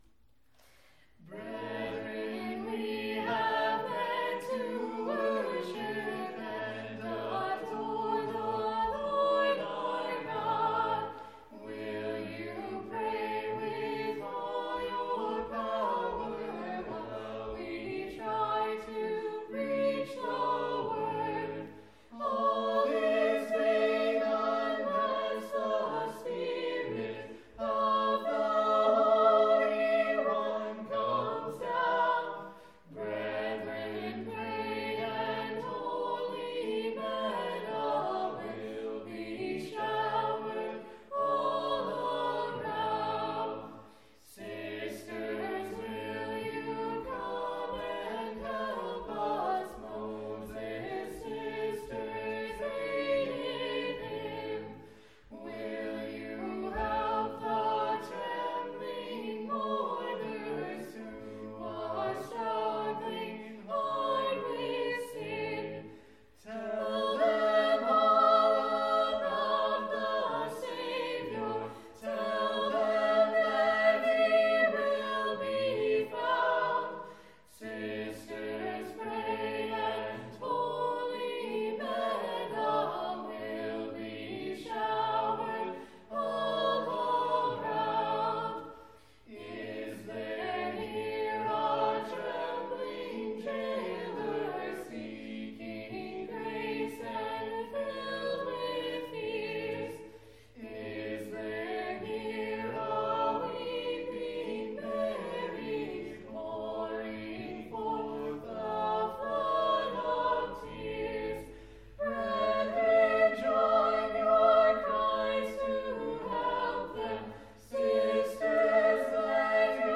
Our Father in Heaven – Sermon